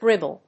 /ˈgrɪbʌl(米国英語)/